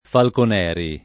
Falconeri [ falkon $ ri ] cogn.